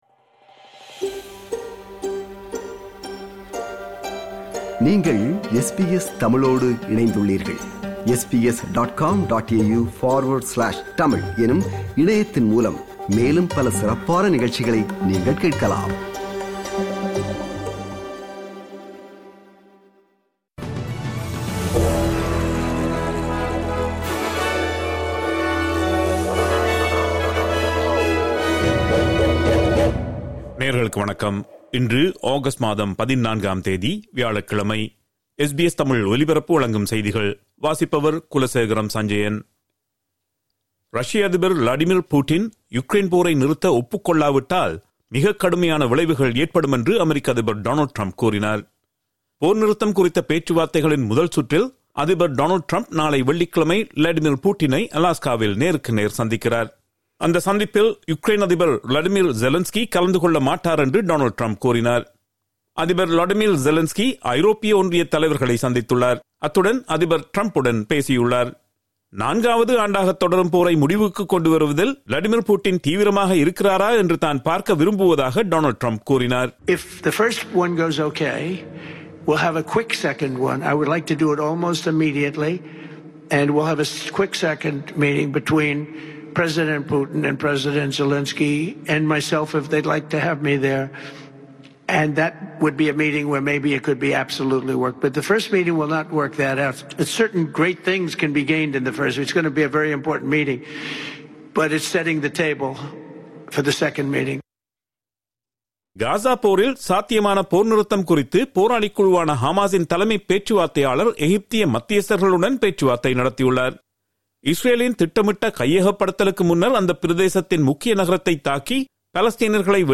SBS தமிழ் ஒலிபரப்பின் இன்றைய (வியாழக்கிழமை 14/08/2025) செய்திகள்.